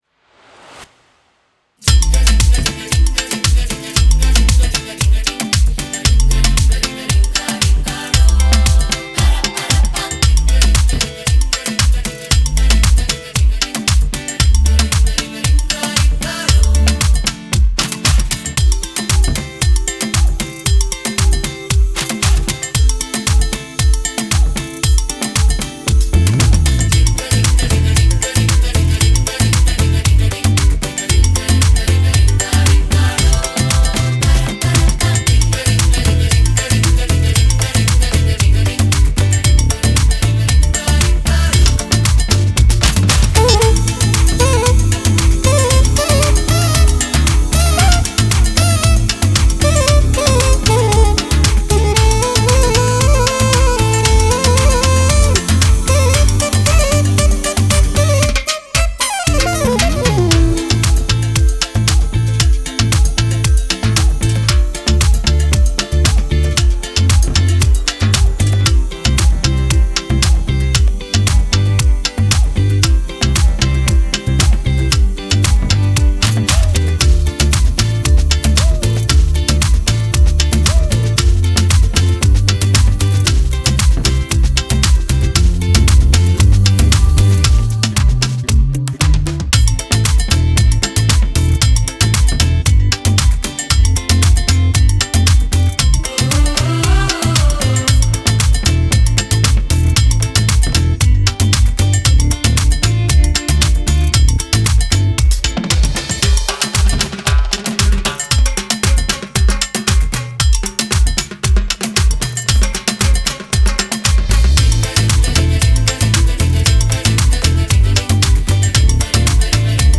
Download the official karaoke version
KARAOKE WITH CHORUS VERSION